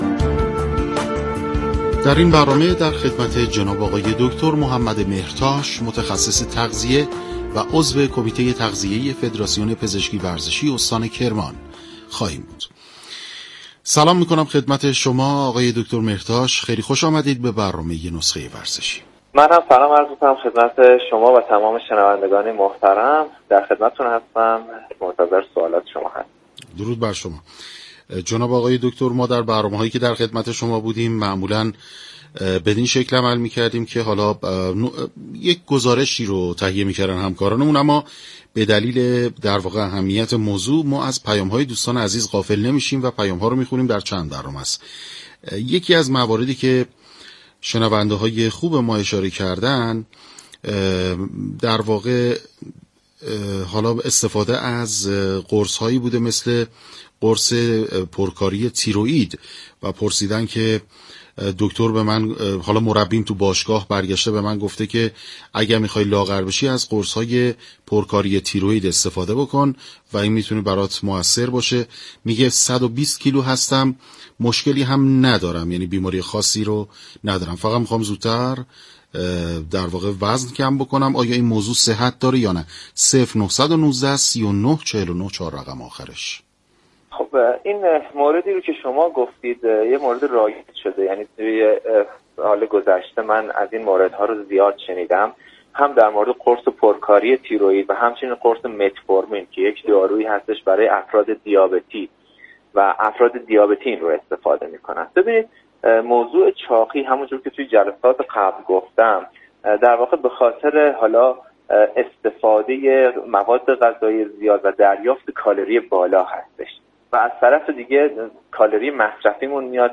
در گفت وگو با برنامه نسخه ورزشی رادیو ورزش